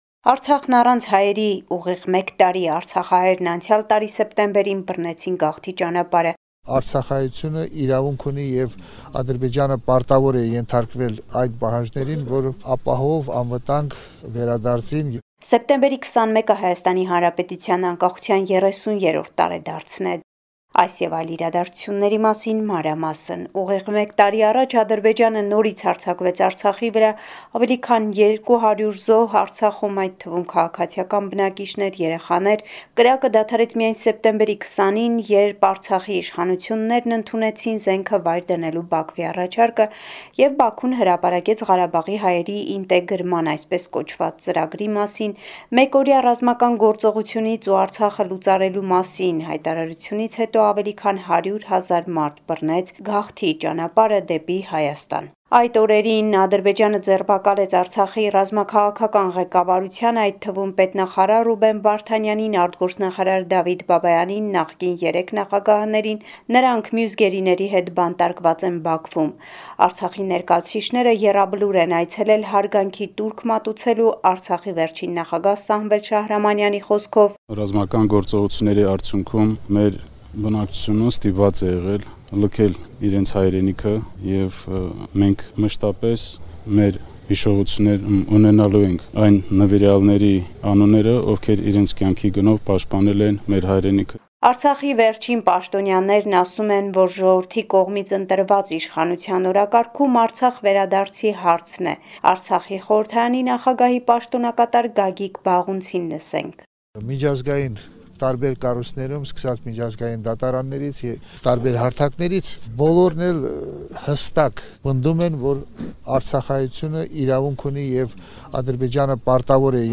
Latest news from Armenia, Artsakh and the Diaspora